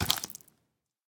assets / minecraft / sounds / mob / drowned / step3.ogg
step3.ogg